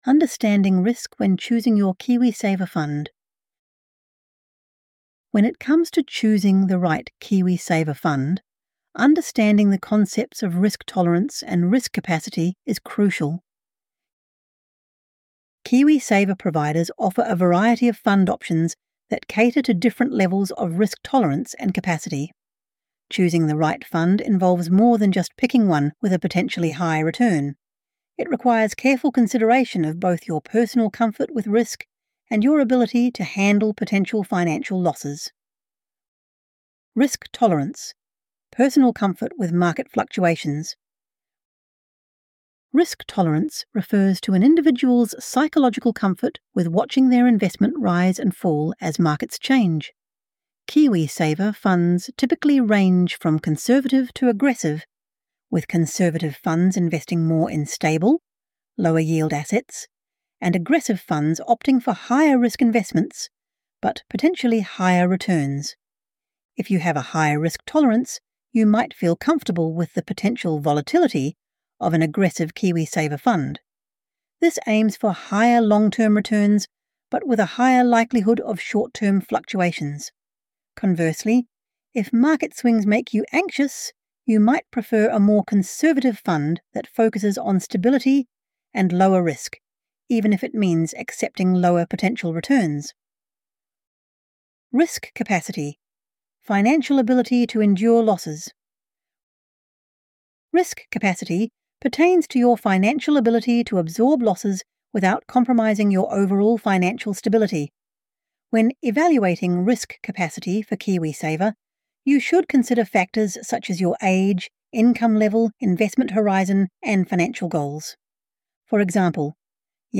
This content features an AI-generated voice for narration purposes.